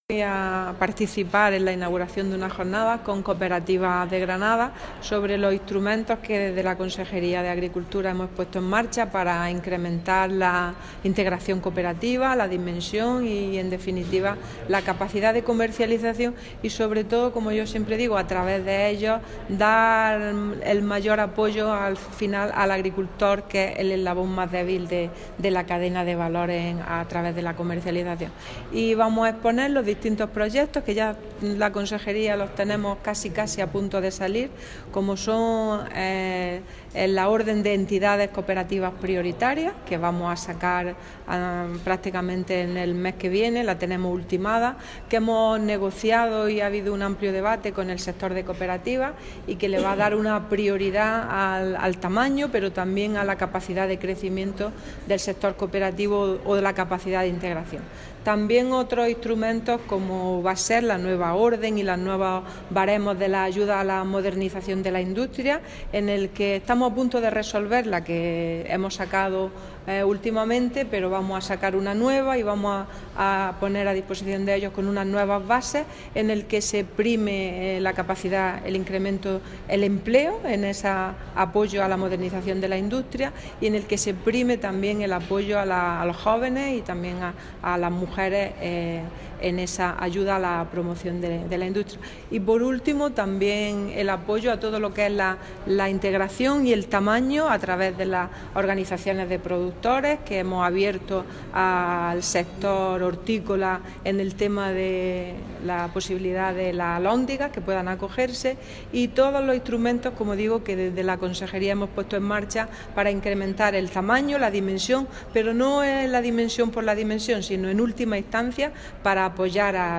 Declaraciones de Carmen Ortiz sobre la jornada técnica 'Instrumentos para el impulso de la dimensión empresarial y la comercialización de las cooperativas'